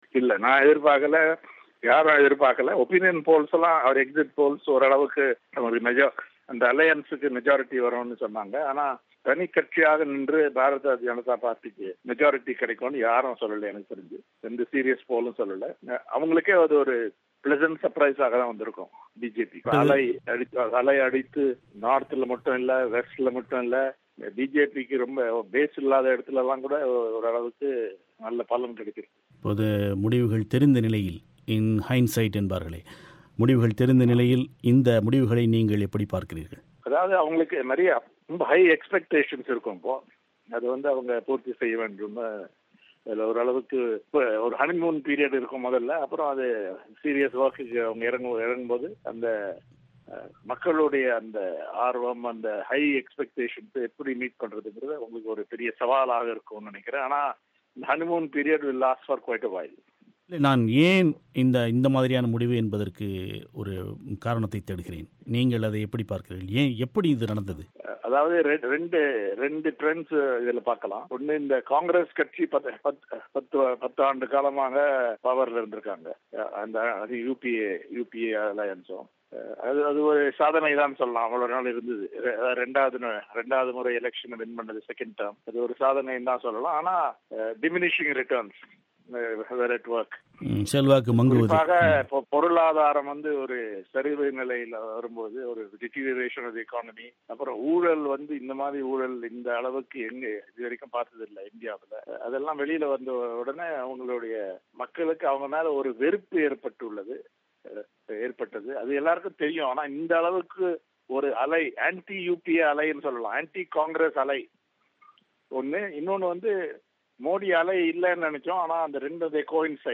எனவே, இந்த முடிவுகள் அரசியல் நோக்கர்களுக்கு ஆச்சர்யத்தைத் தருகின்றனவா? இந்து பத்திரிகைக் குழுமத்தின் தலைவர் என்.ராம் அவர்களைக் கேட்டார்